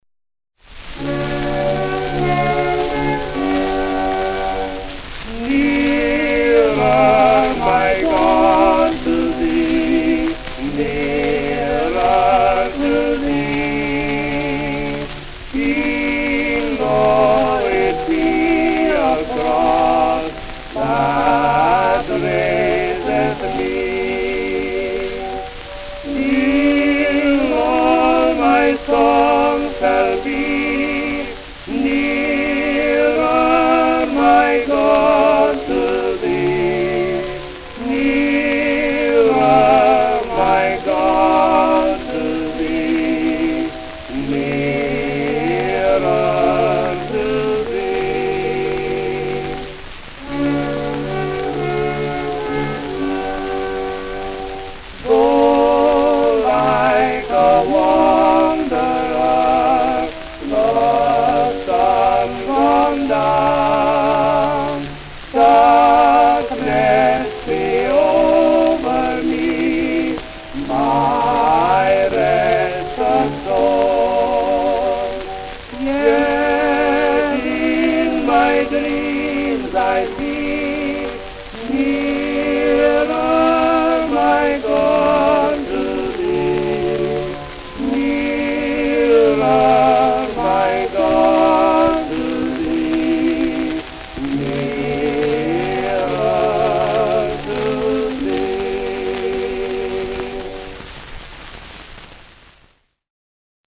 Tenor Duet